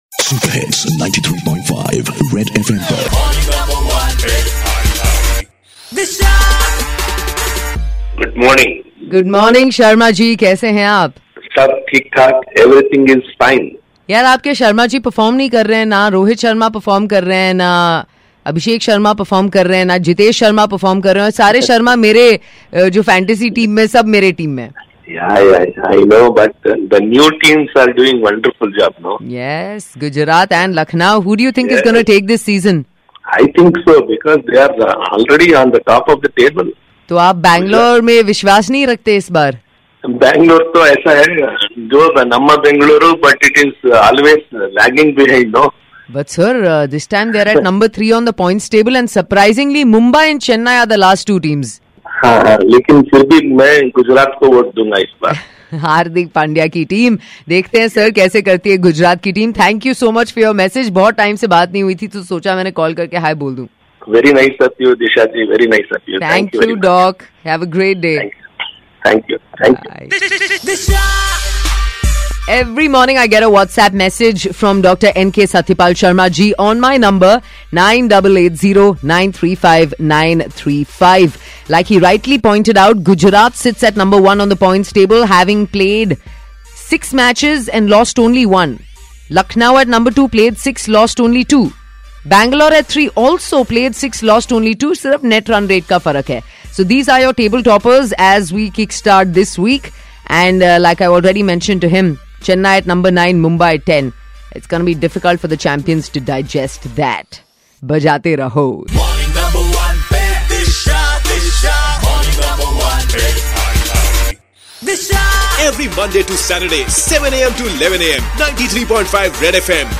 talks to listener